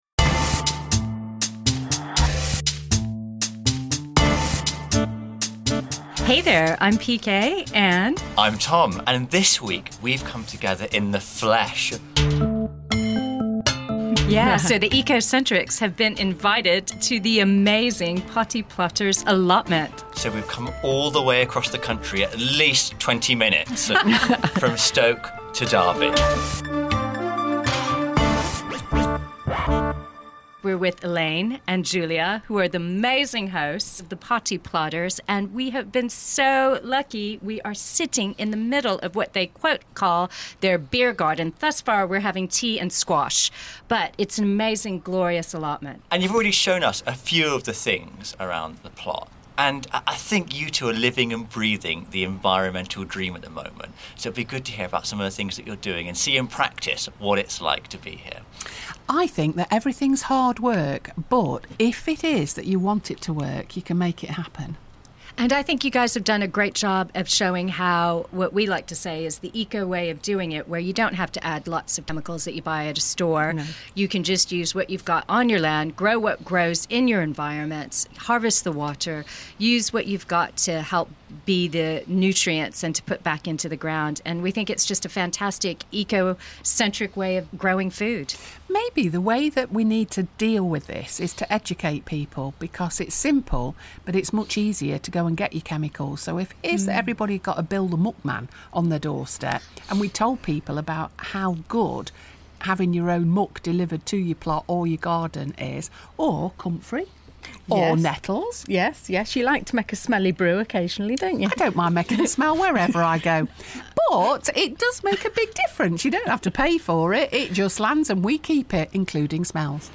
take a trip to a Derby City allotment